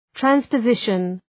Προφορά
{,trænspə’zıʃən}